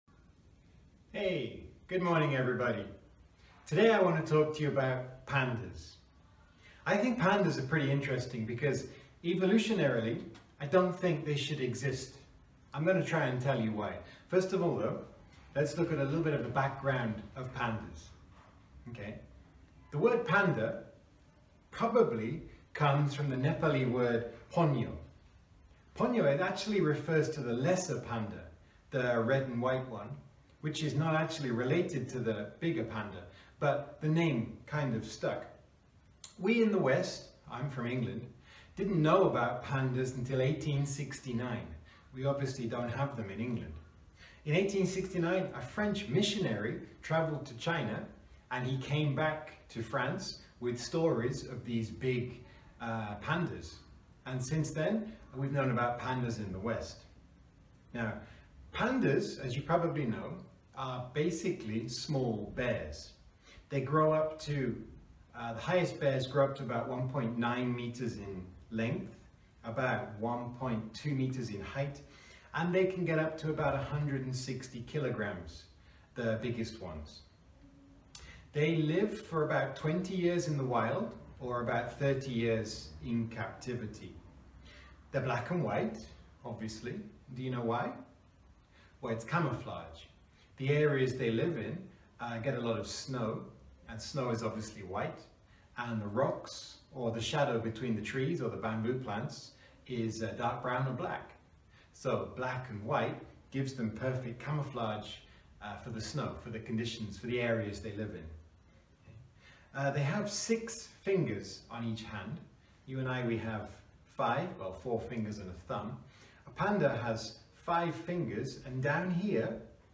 Posted in Long talk | Tagged , , | Comments Off on #3 Pandas